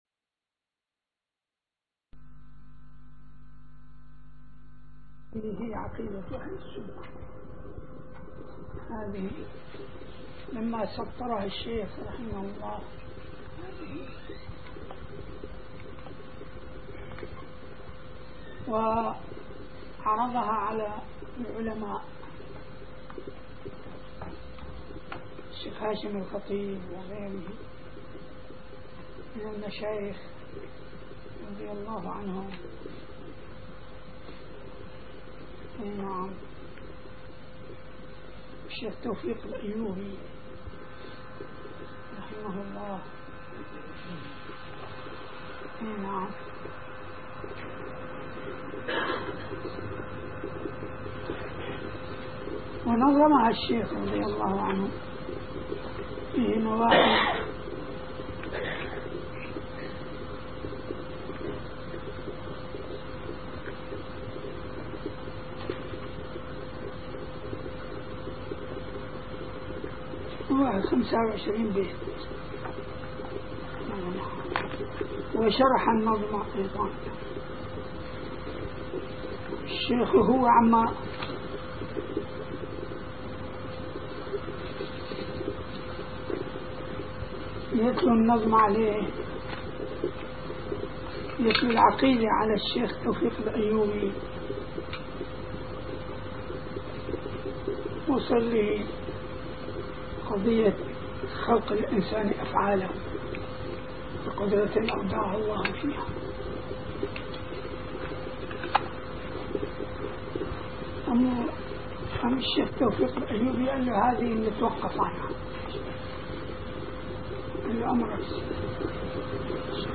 - الدروس العلمية - شرح كتاب مفتاح الجنة والوظيفة الشاذلية - الدرس الأول: من الصفحة 1 إلى الصفحة 12